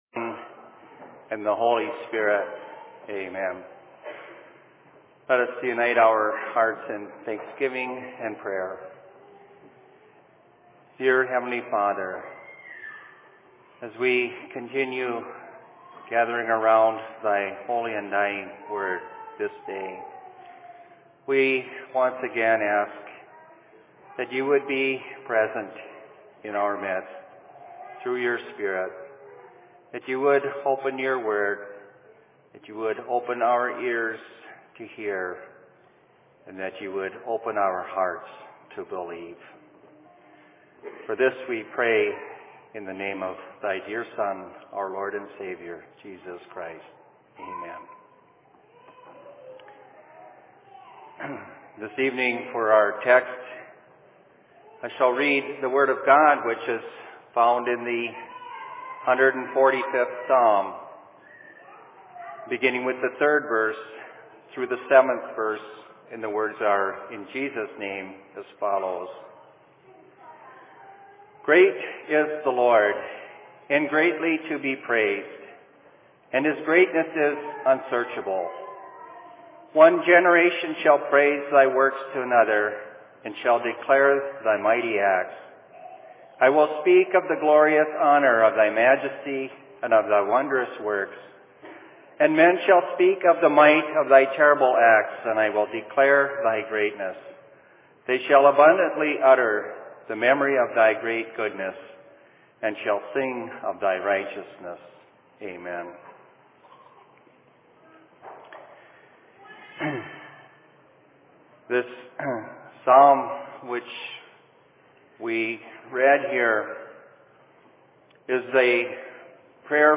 Sermon in Rockford 24.07.2011
Location: LLC Rockford